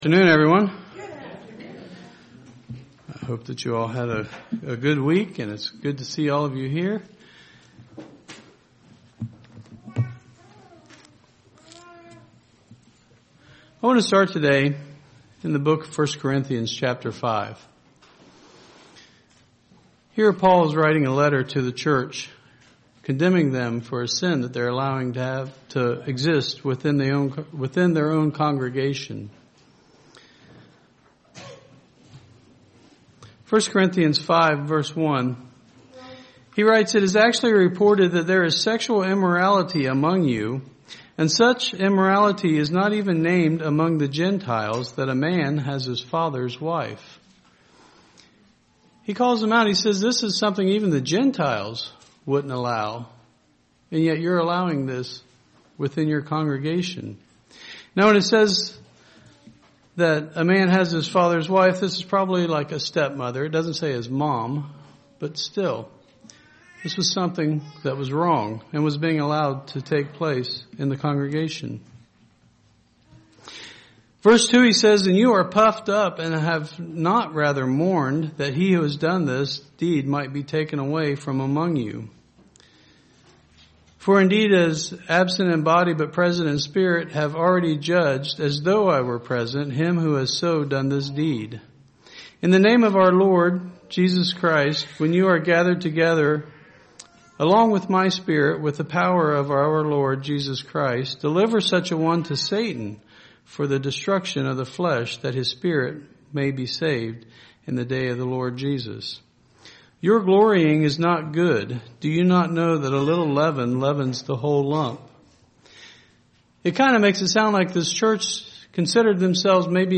This sermon covers the events that took place on the last day that Christ was on Earth as a physical human being. Why did these things have to happen? What was the purpose of the suffering and the sacrifice?